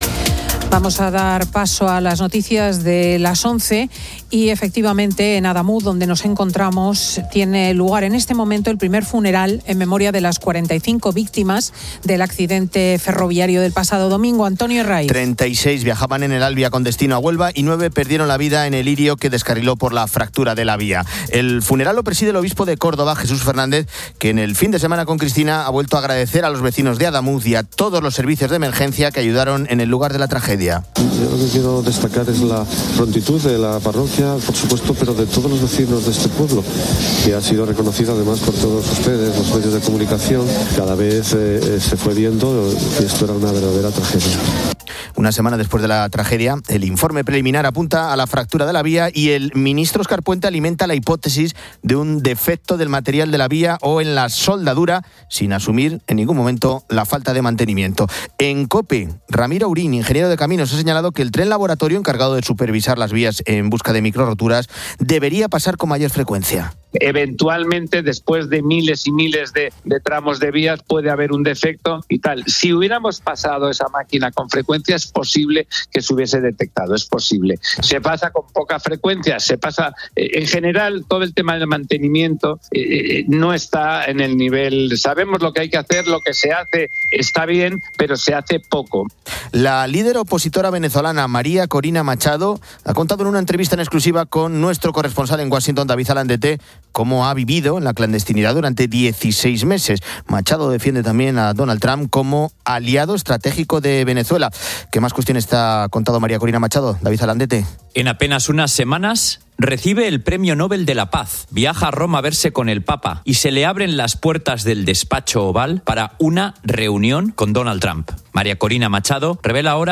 Hablamos con vecinos de Adamuz y con el ingeniero industrial experto en diseño ferroviario